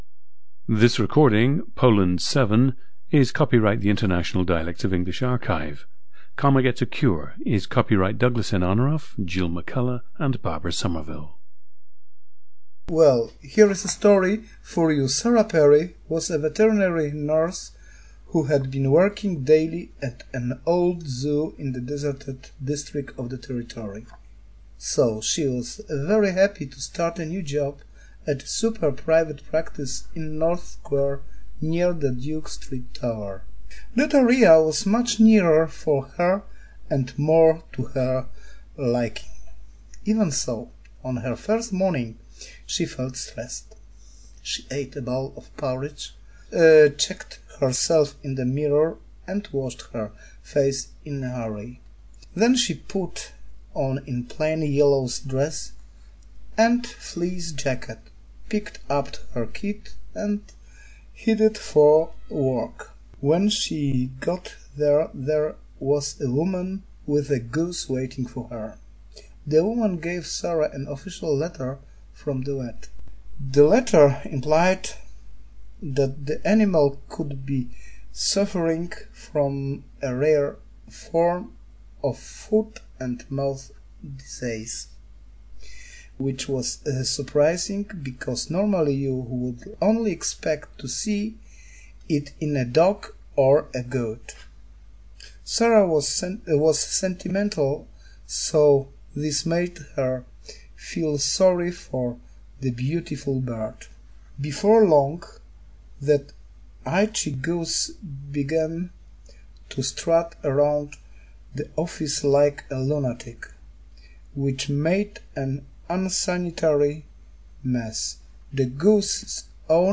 Listen to Poland 7, a 50-year-old man from Gdańsk, Poland.
GENDER: male
The subject started learning English in high school.
• Recordings of accent/dialect speakers from the region you select.
The recordings average four minutes in length and feature both the reading of one of two standard passages, and some unscripted speech.